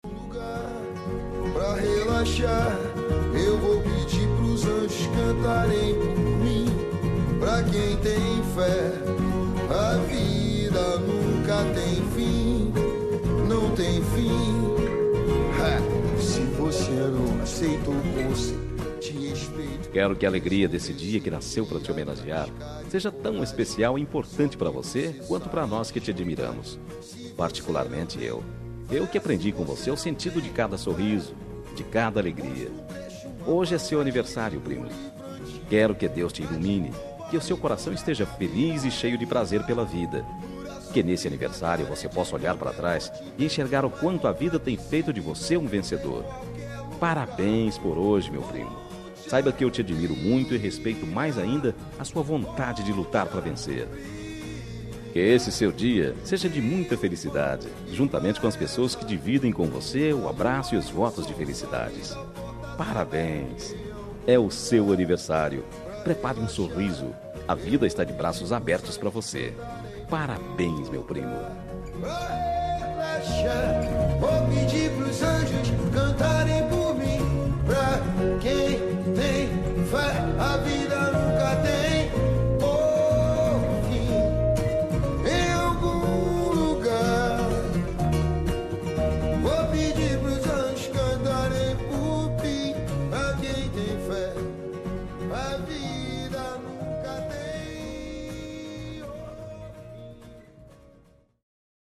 Aniversário de Primo – Voz Masculina – Cód: 042836